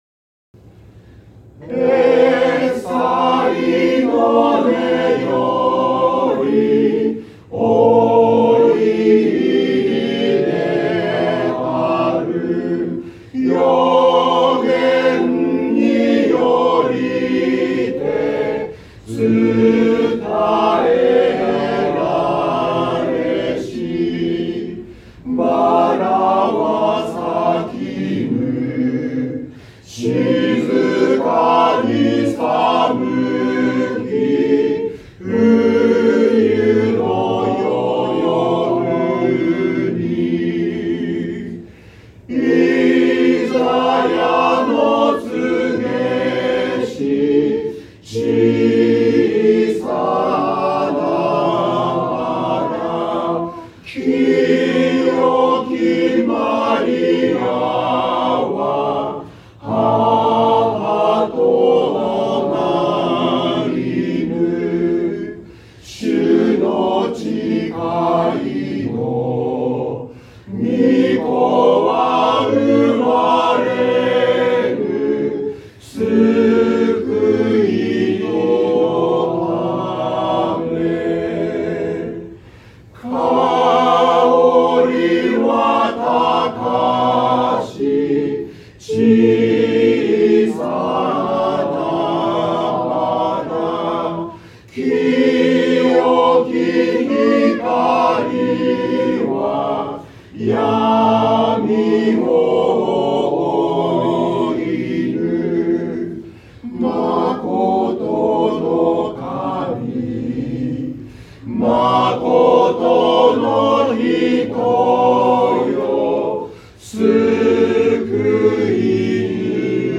聖歌隊による賛美の歌声
礼拝での奉仕の様子